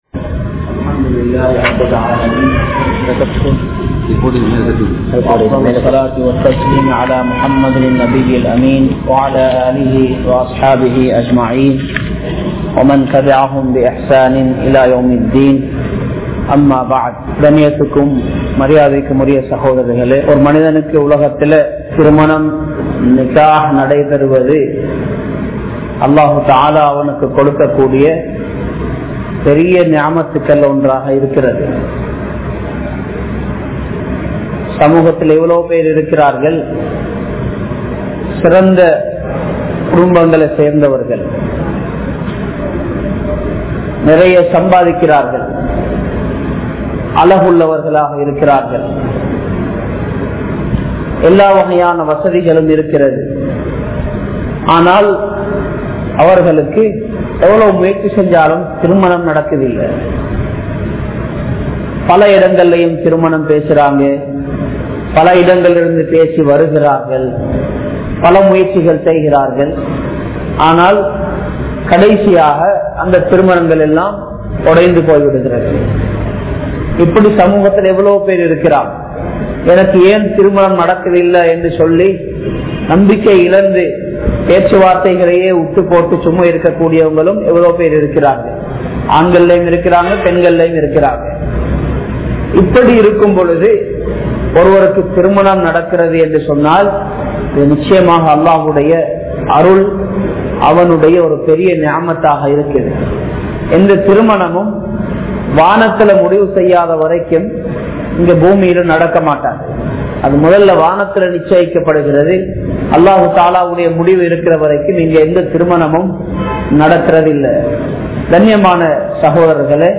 Kanavan & Manaivien Anpu(LOVE) | Audio Bayans | All Ceylon Muslim Youth Community | Addalaichenai
Majma Ul Khairah Jumua Masjith (Nimal Road)